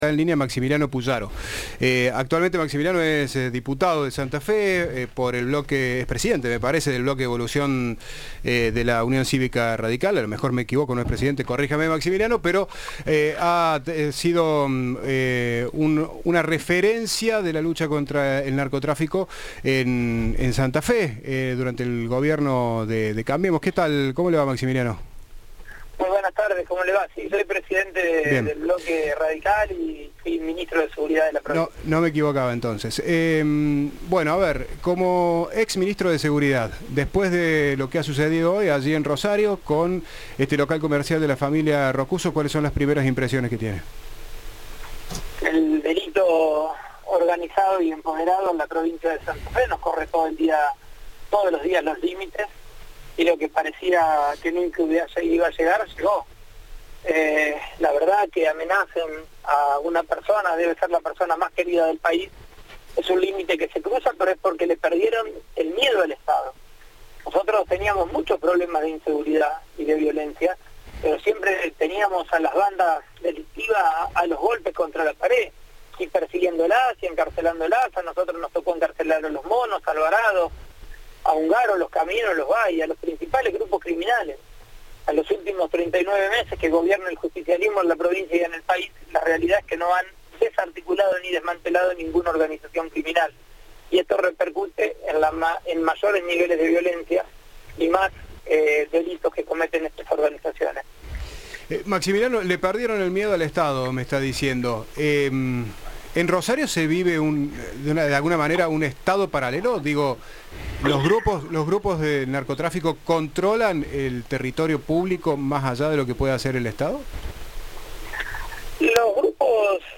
Entrevista de Informados, al regreso.